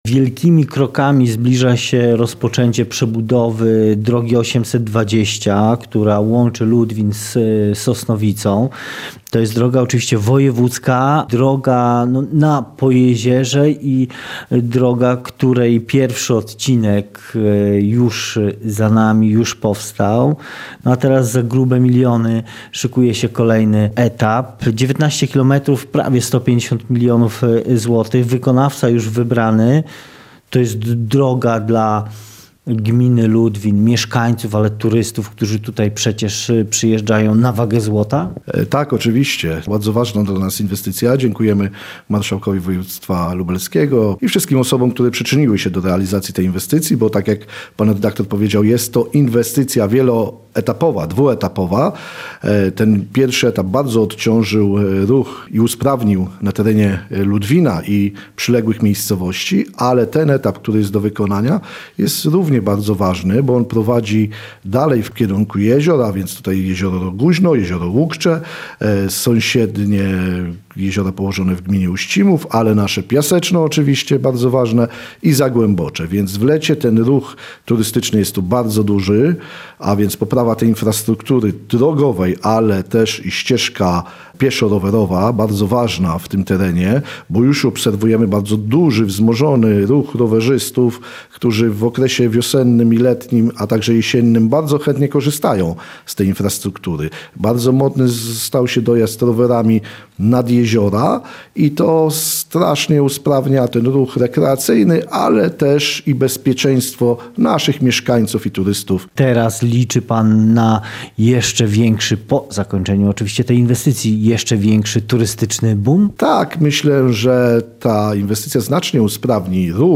Rozmowa z wójtem gminy Ludwin Andrzejem Chabrosem